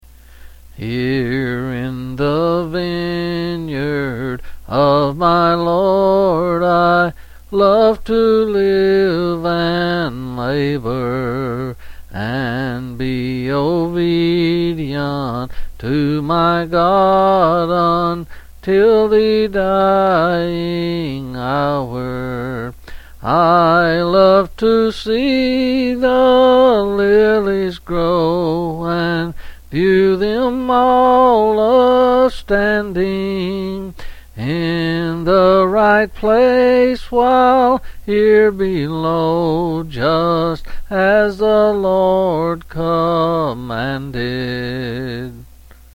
Quill Selected Hymn
8s and 7s.